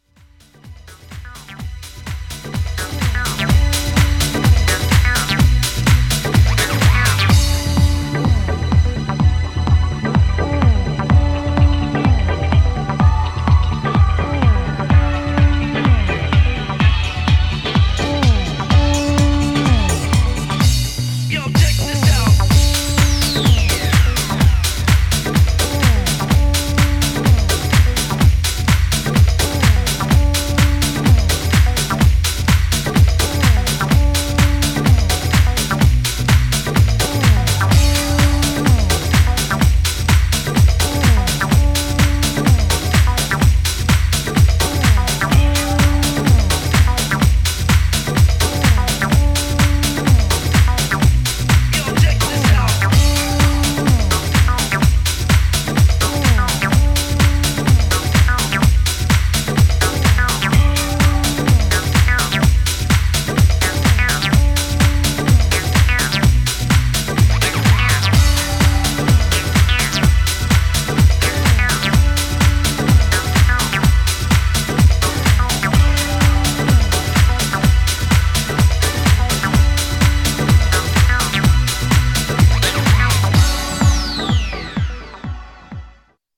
Styl: Disco, Progressive, House, Trance